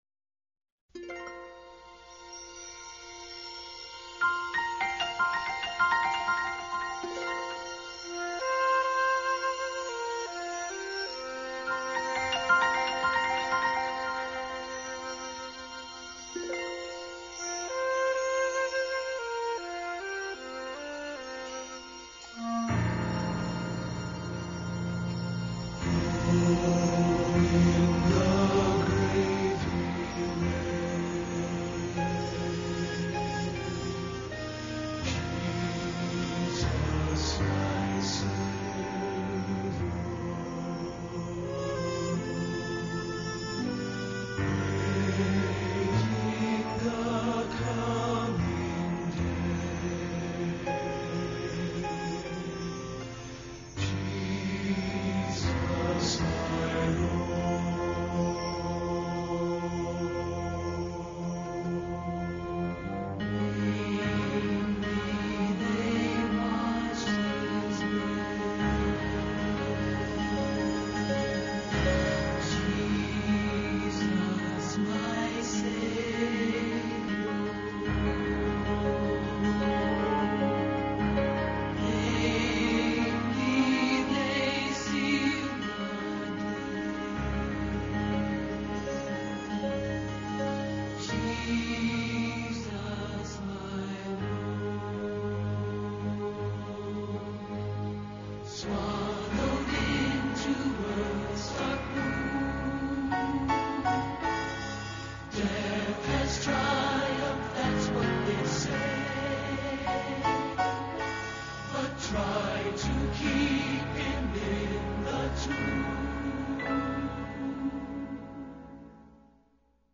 This week’s Devotional Topic On this weeks special Easter program I will change my devotional for this week’s program to a reading called, The Ragman. This tells a story about “The Ragman” who travels around the country and trades clean cloths to individuals who suffer ills and hurts.